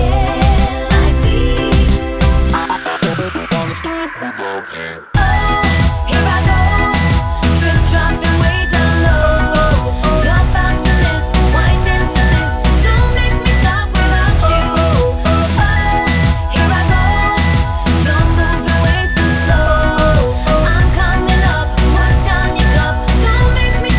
Duet Mix Pop (2010s) 3:38 Buy £1.50